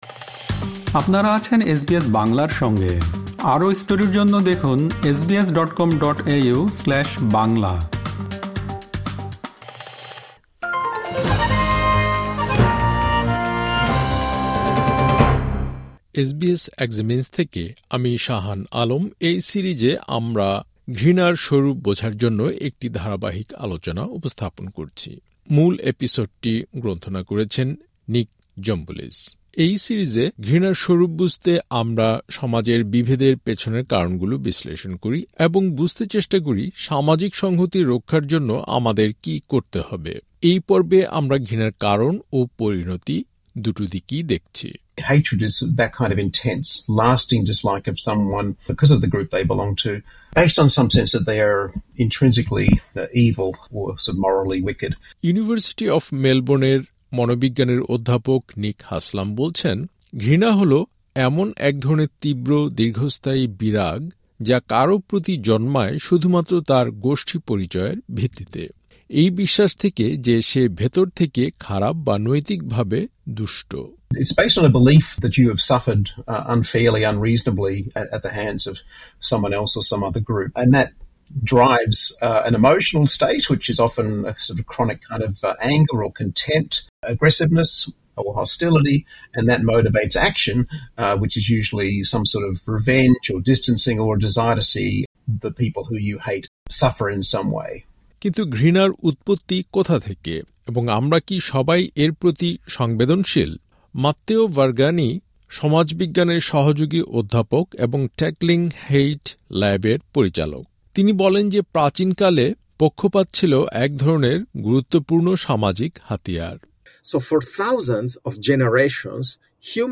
এসবিএস এক্সামিনস-এর এই পর্বে ঘৃণার মানসিক উৎস এবং সমাজে চরম বিভক্তির কী প্রভাব পড়ে, তা বিশ্লেষণ করা হয়েছে। সম্পূর্ণ প্রতিবেদনটি শুনতে উপরের অডিও-প্লেয়ারটিতে ক্লিক করুন।